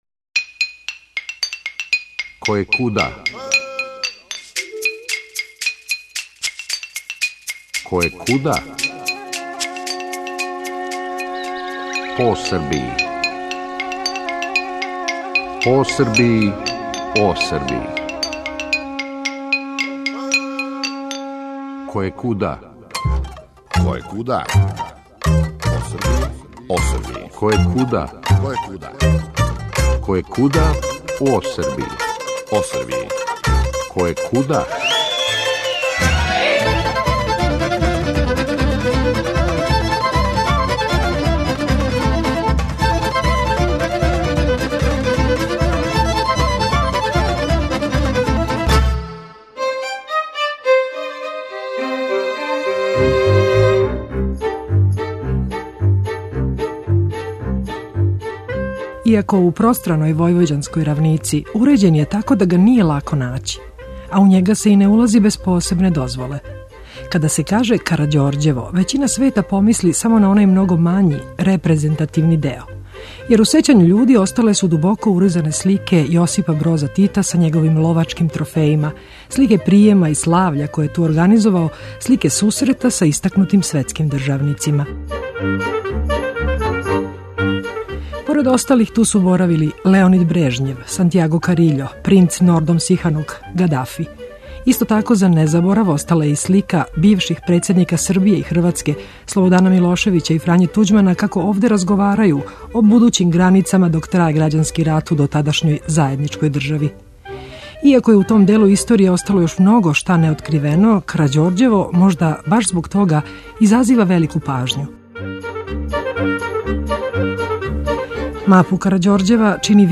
Срели смо га у Бачкој Паланци, где смо и забележиле ову Којекуда причу.